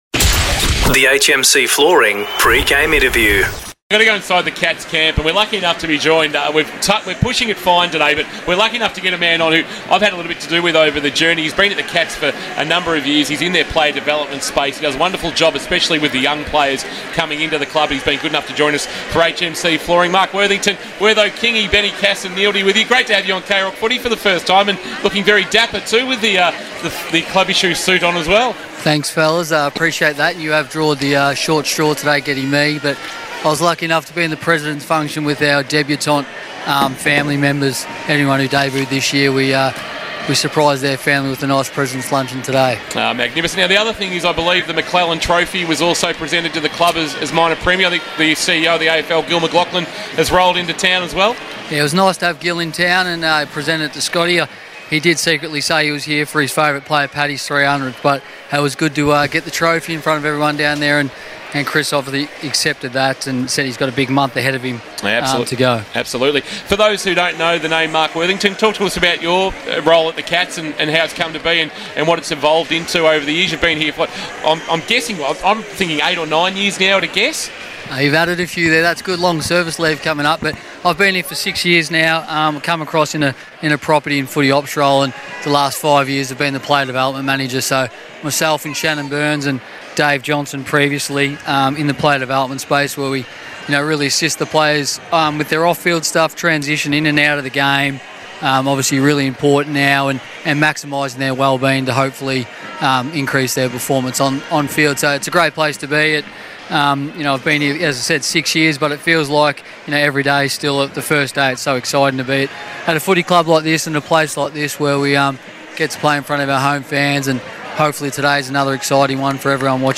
2022 - AFL ROUND 23 - GEELONG vs. WEST COAST: Pre-match Interview